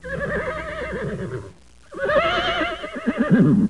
Horse Sound Effect
Download a high-quality horse sound effect.
horse-1.mp3